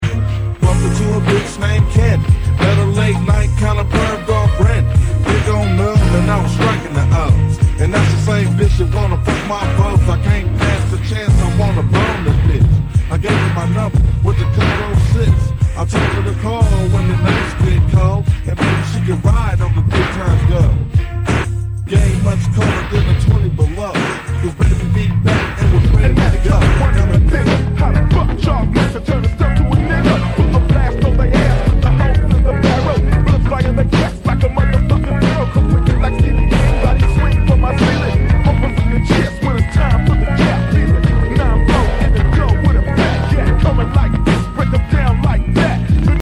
mid 90’s gangsta rap mixtape